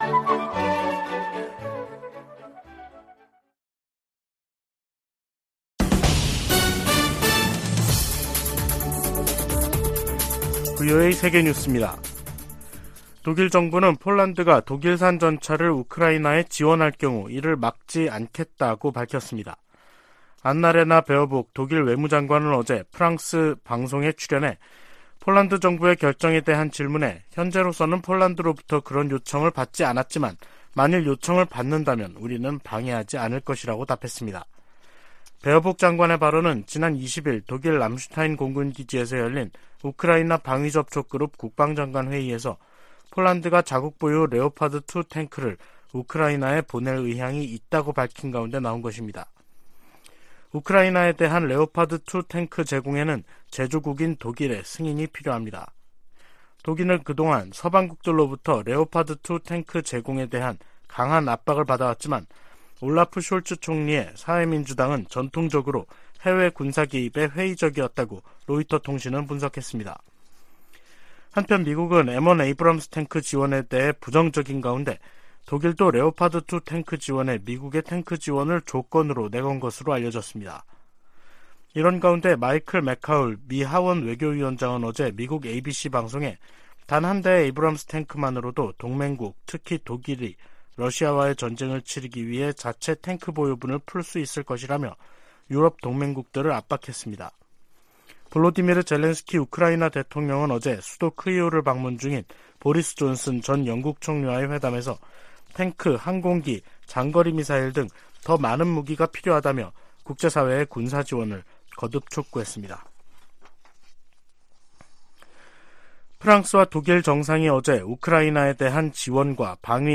세계 뉴스와 함께 미국의 모든 것을 소개하는 '생방송 여기는 워싱턴입니다', 2023년 1월 23일 저녁 방송입니다. 독일이 폴란드의 대우크라이나 전차 지원을 승인할 수 있다는 입장을 밝혔습니다. 아시아계가 많이 거주하는 미 서부 로스앤젤레스 카운티에서 음력설 전날 총기 난사 사건이 발생해 최소한 10명이 숨졌습니다.